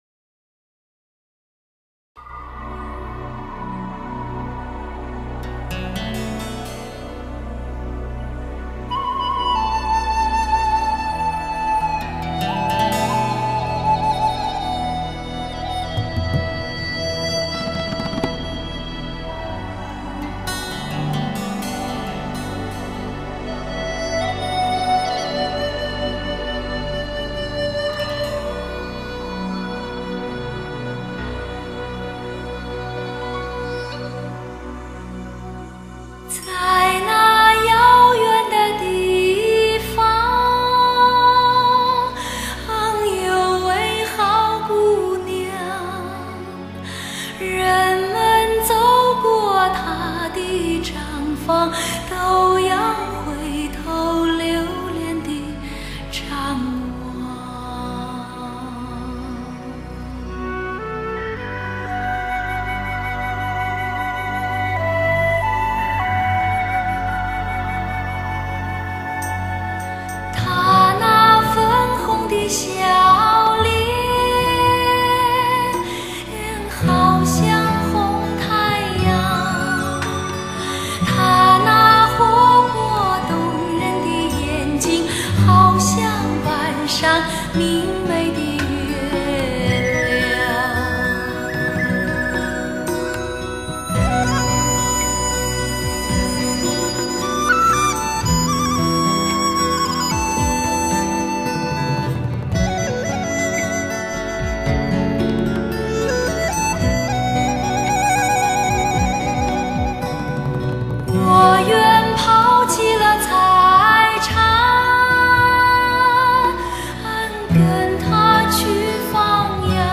经典民谣 发烧真声 充满磁性
其精髓在忠于中国传统音乐文化的基础上融入西方不同地域的音乐文化特色，如爵士、布鲁斯、拉丁和华尔兹等。